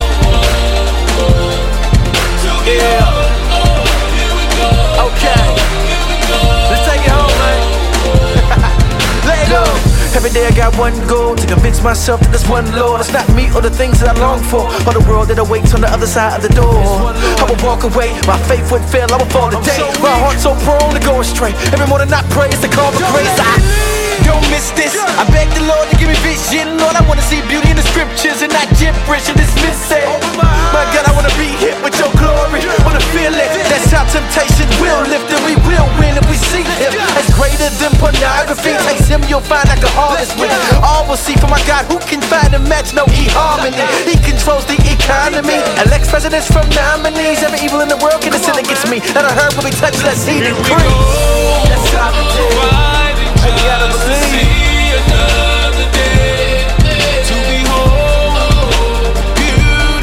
deren Sound von Urban-Beats geprägt ist.
• Sachgebiet: Rap & HipHop